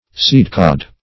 seedcod - definition of seedcod - synonyms, pronunciation, spelling from Free Dictionary Search Result for " seedcod" : The Collaborative International Dictionary of English v.0.48: Seedcod \Seed"cod`\, n. A seedlip.